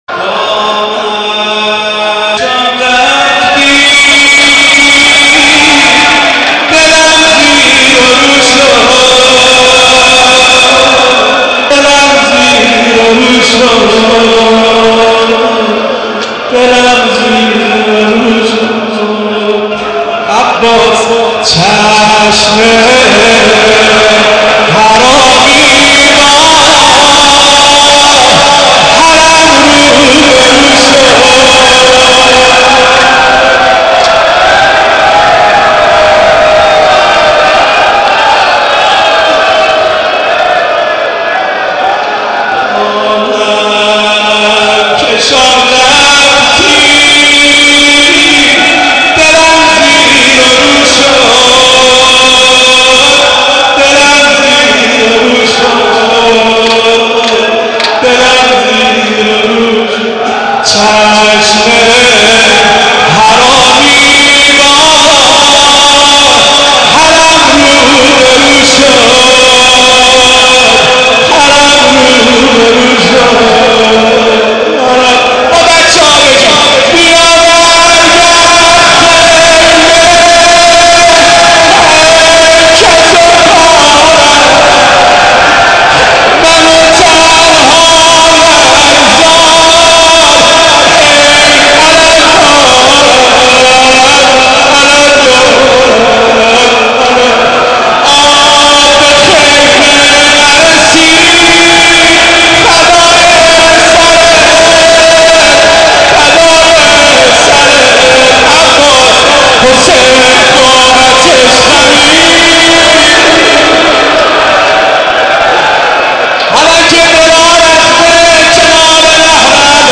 مداحی شب سی ام ماه مبارک در حسینیه دلریش